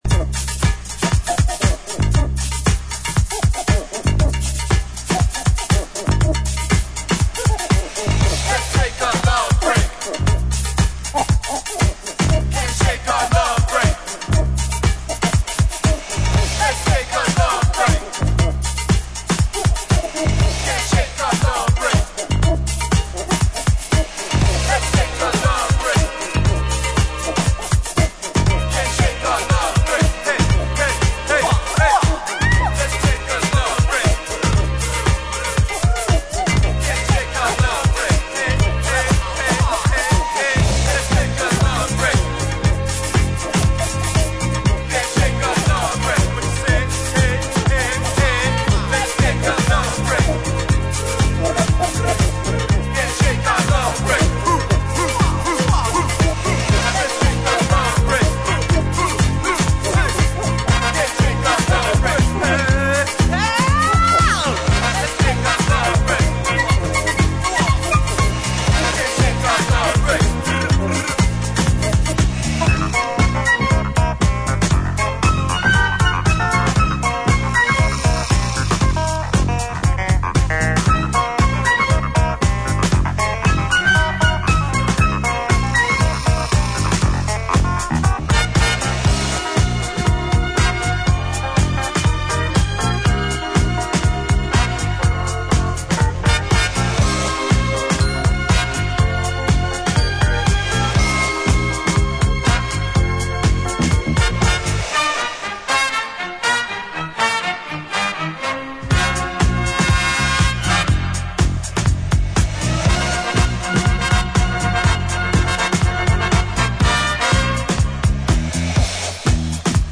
ジャンル(スタイル) DISCO / SOUL / FUNK / RE-EDIT